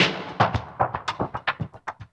DWS SWEEP2-L.wav